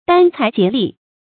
殚财竭力 dān cái jié lì
殚财竭力发音